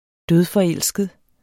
Udtale [ ˈdøðˈ- ]